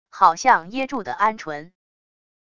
好像噎住的鹌鹑wav音频